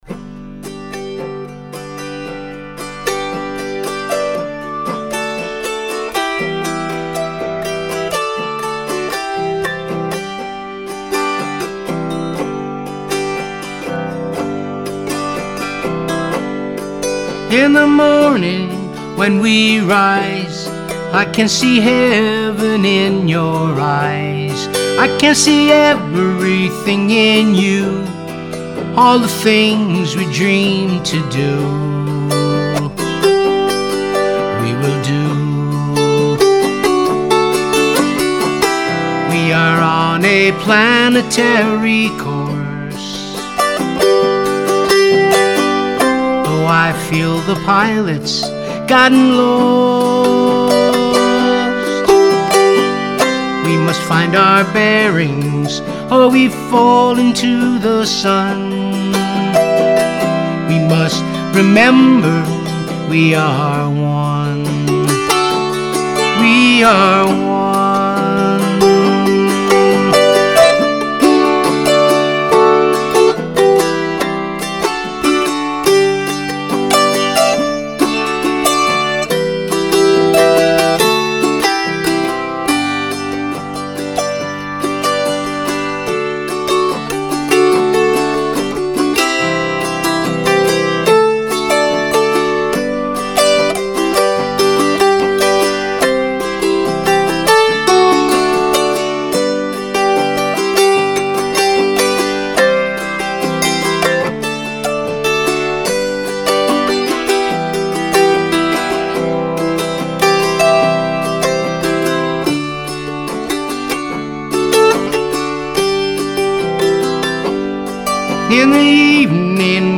Recorded at Clinton Studios New York City